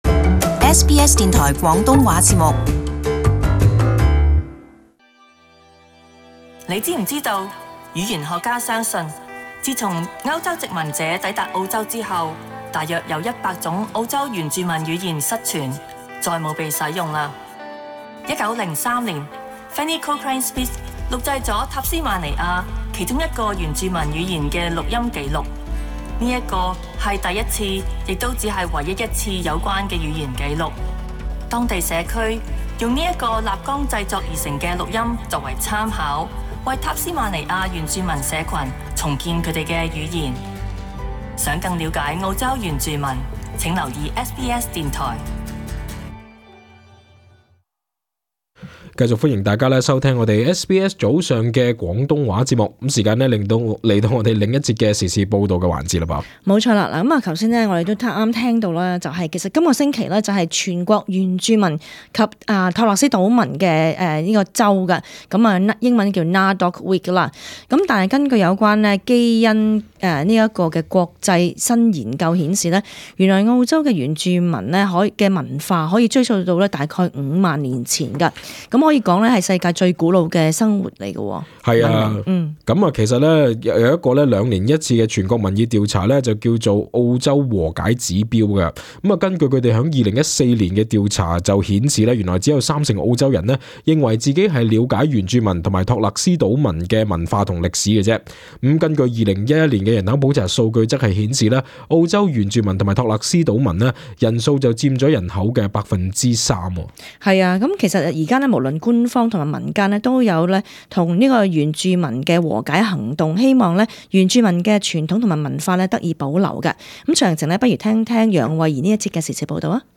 【時事報導】澳洲原住民及島民週